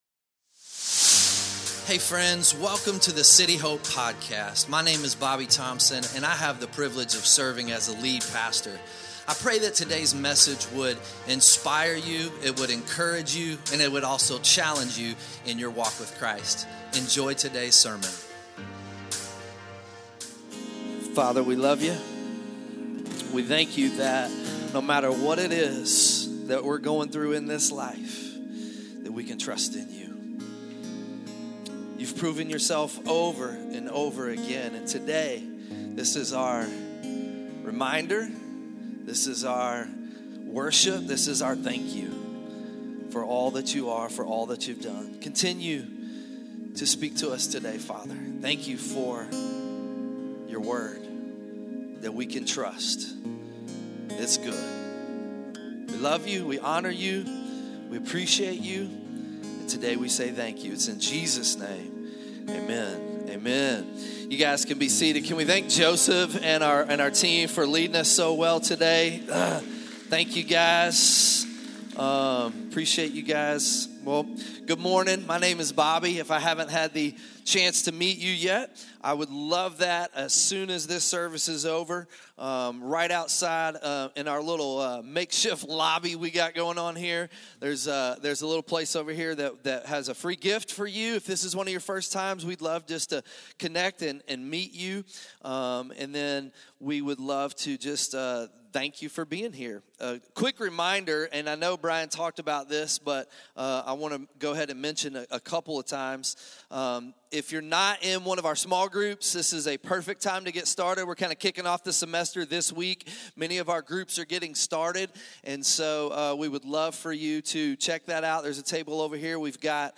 2026 Sunday Morning Vision doesn’t move with agreement.